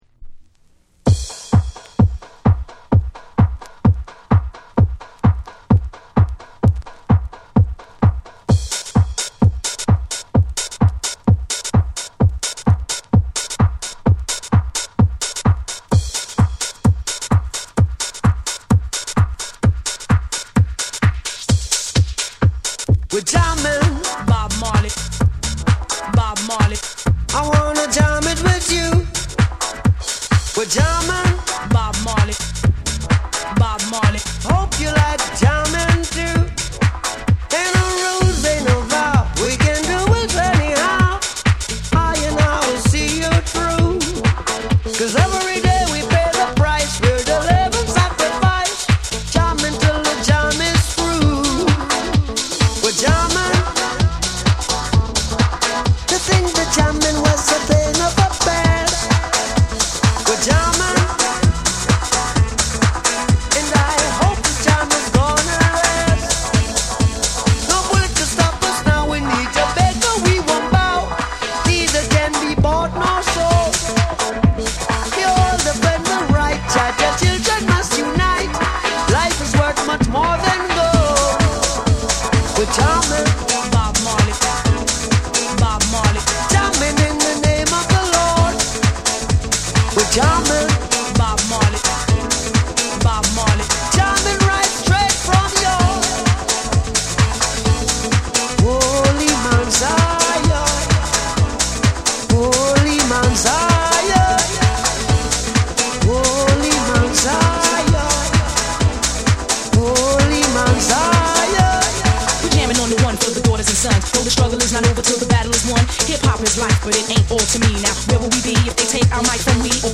フロア映えするダンス・ナンバーにリミックス！
REGGAE & DUB / TECHNO & HOUSE